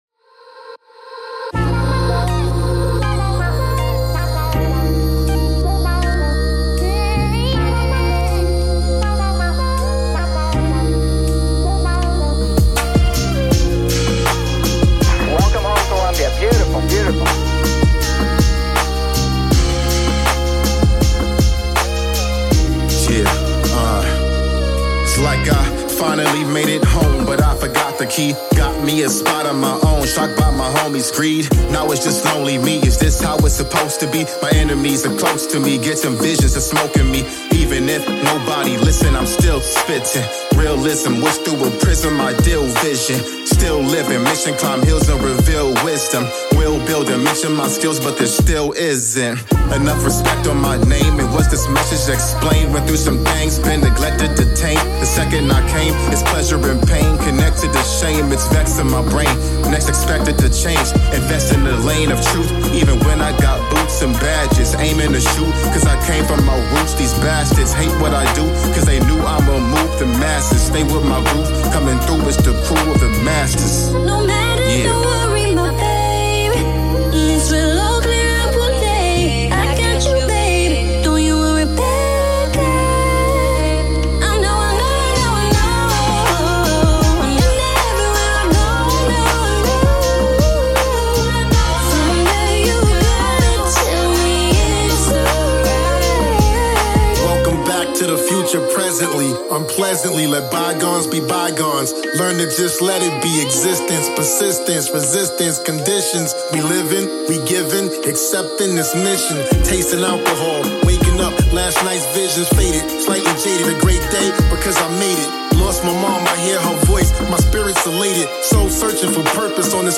encouraging chorus